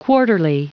Prononciation du mot quarterly en anglais (fichier audio)
Prononciation du mot : quarterly